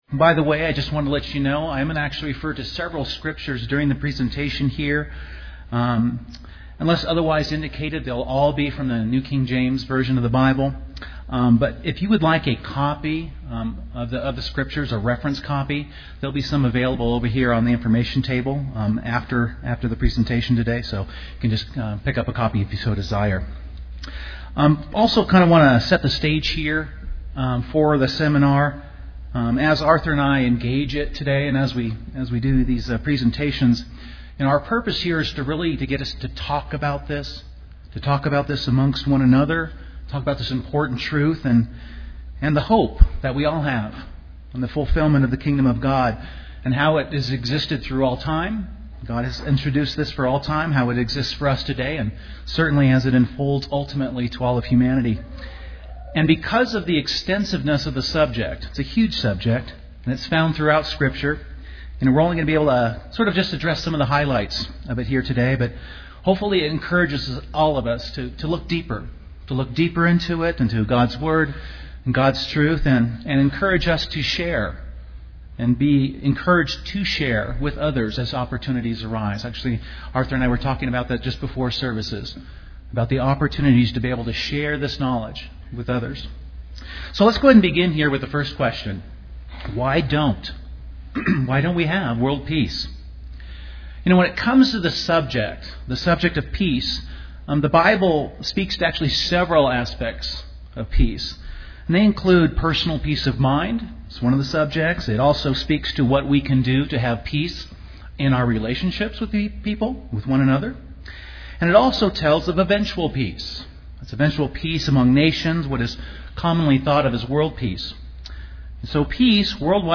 God will use people today who are learning how to rule themselves and growing in favor and knowledge in preparation for the Kingdom of God in the future. Learn more in part 2 of this Kingdom of God seminar.
Given in Colorado Springs, CO